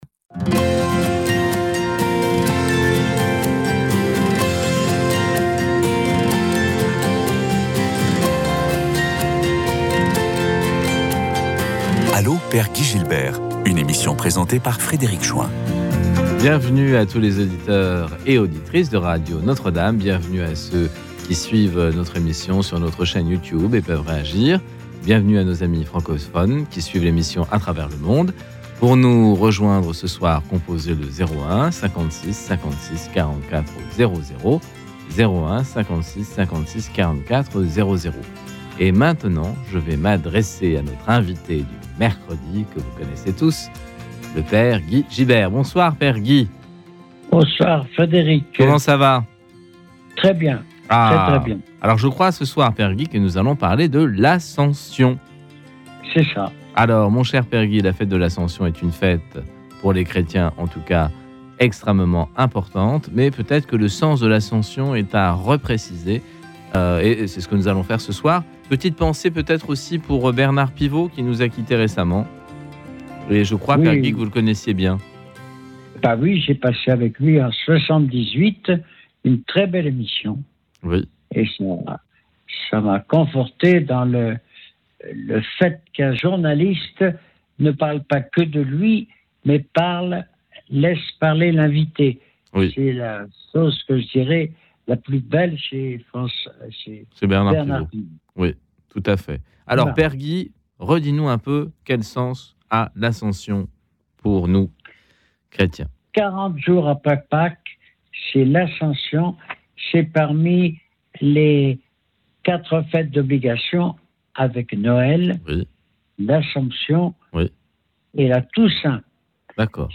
commente avec son franc-parler habituel l’actualité du monde….